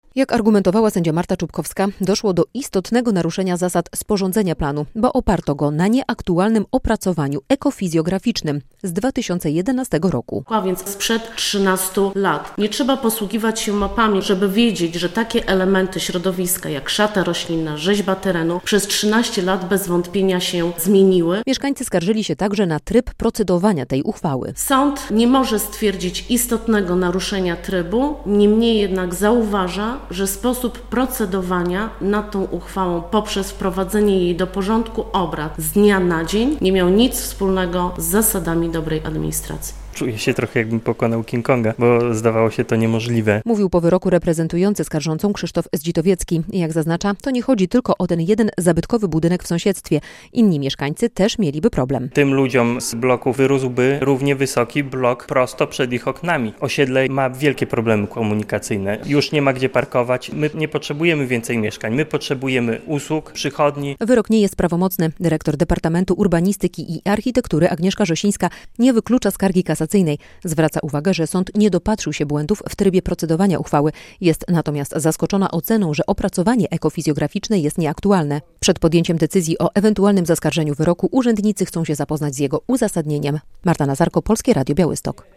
Sąd po stronie mieszkańców - relacja